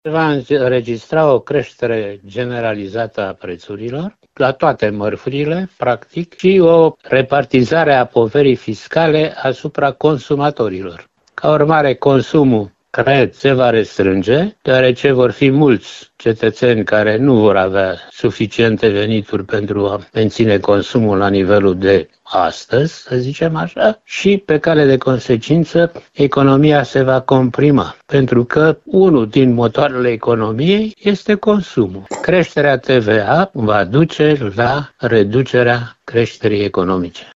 Profesor de economie: Creșterea TVA va duce la reducerea creșterii economice